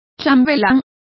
Also find out how chambelanes is pronounced correctly.